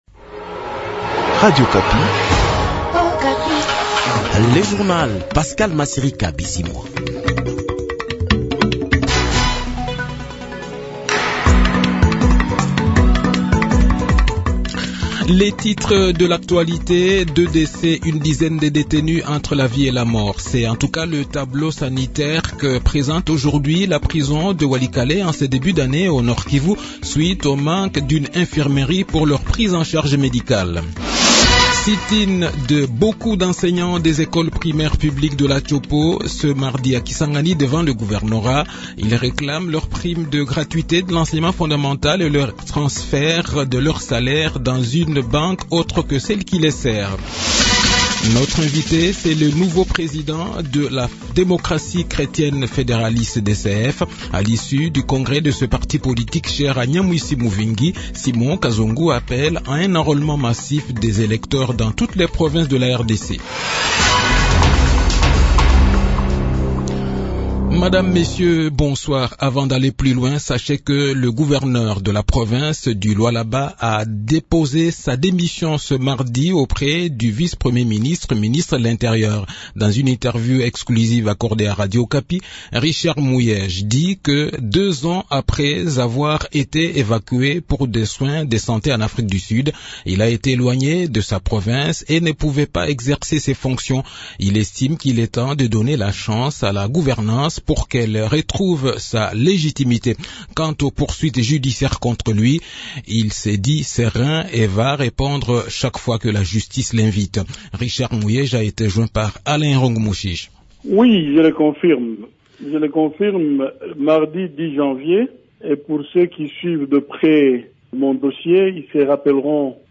Journal Soir
Le journal de 18 h, 10 janvier 2023